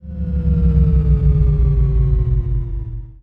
deceleration.wav